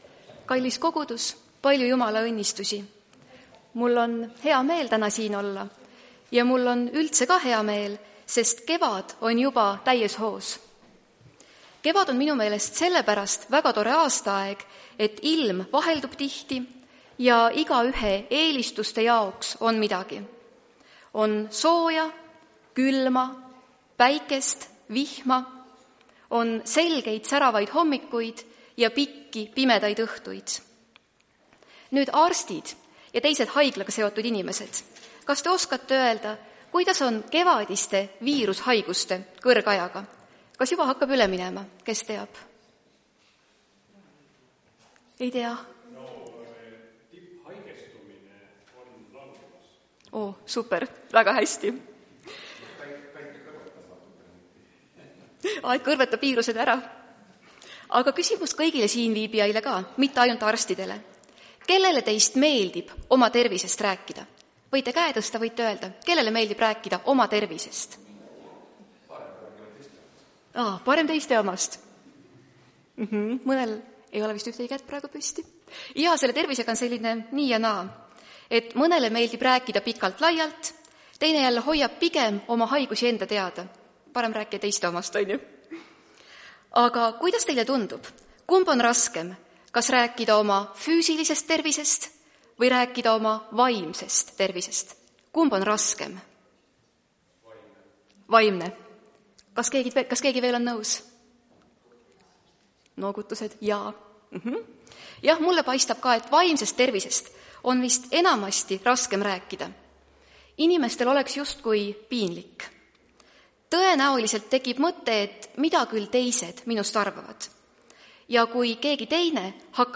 Tartu adventkoguduse 11.04.2026 teenistuse jutluse helisalvestis.